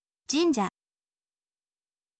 jinja